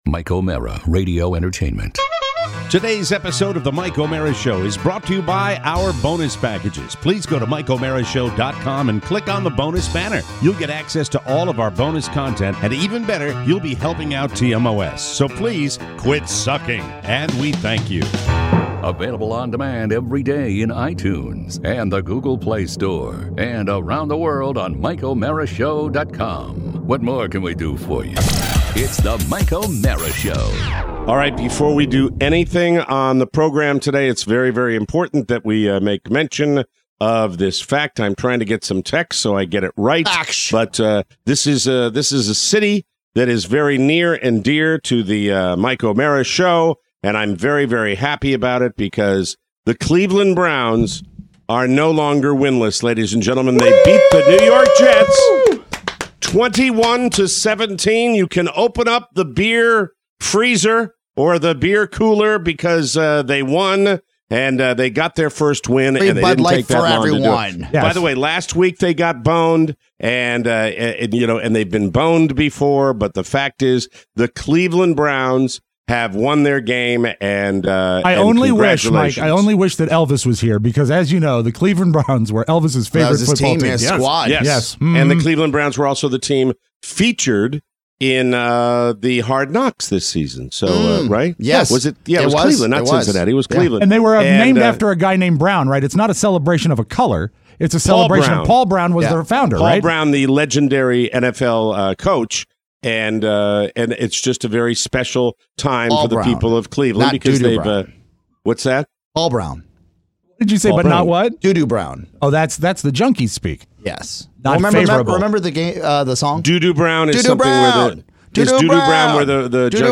Today… we are joined in studio by funnyman